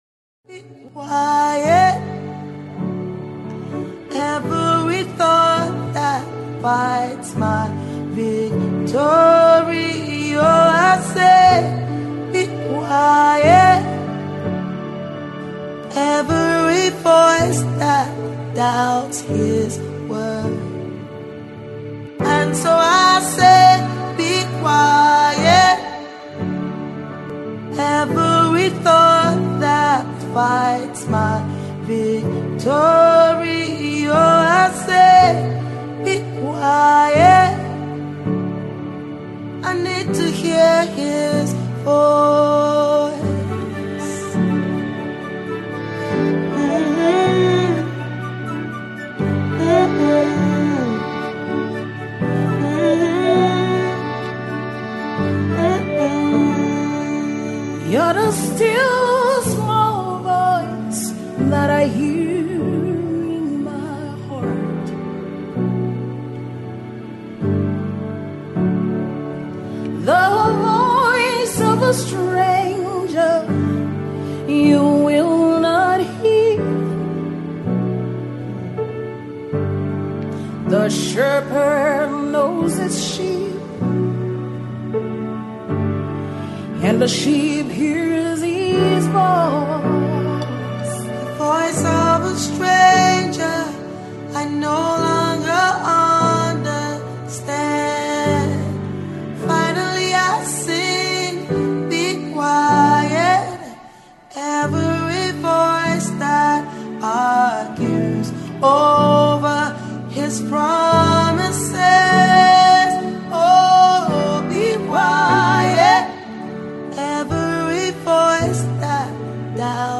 fresh uplifting session
Pianist